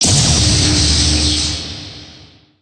electro_start.wav